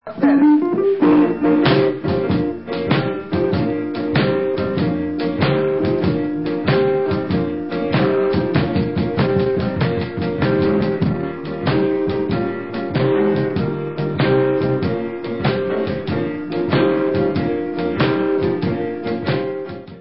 [Rehearsal Take]